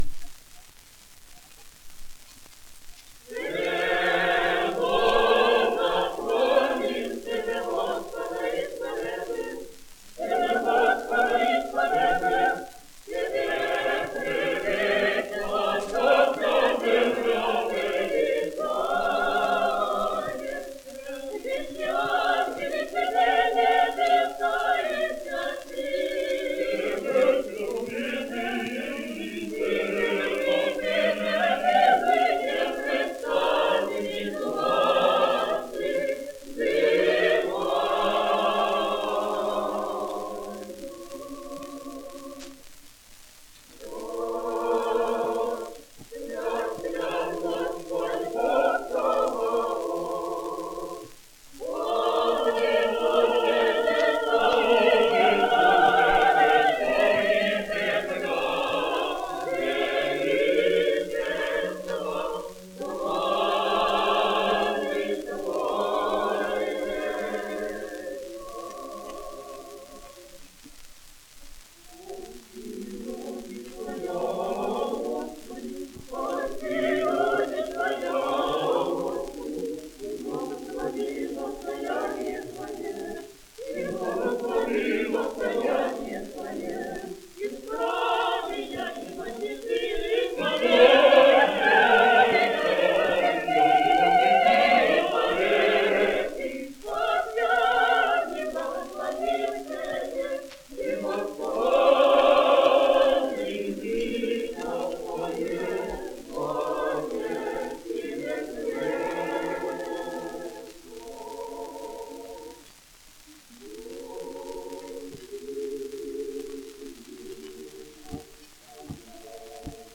Prior to the First World War, and the Russian Revolution, the only place to hear Russian choral singing in America was in the Russian Orthodox Churches, which were built by Russian immigrants between 1880 and 1910.
Russian Orthodox Cathedral Choir of Paris